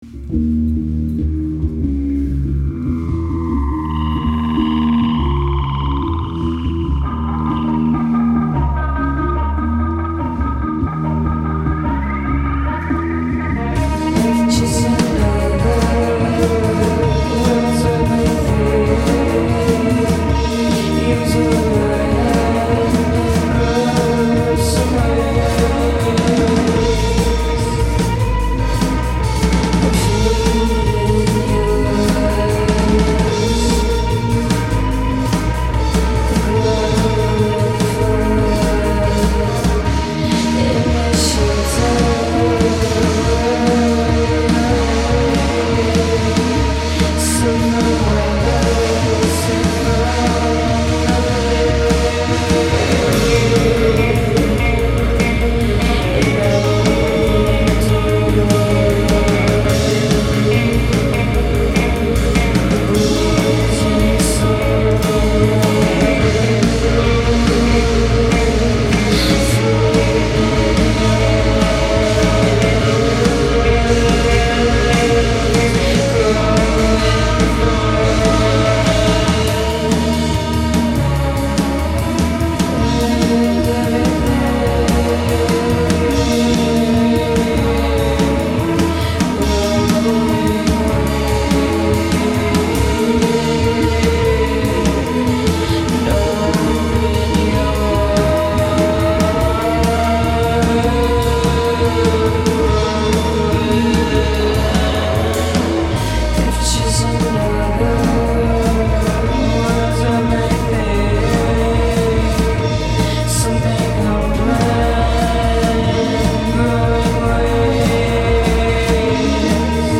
We had a blast this week with the moody alternative band The Velvet Pouch.